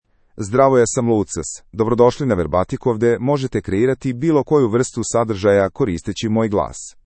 MaleSerbian (Serbia)
Lucas — Male Serbian AI voice
Voice sample
Male
Lucas delivers clear pronunciation with authentic Serbia Serbian intonation, making your content sound professionally produced.